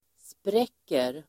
Uttal: [spr'ek:er]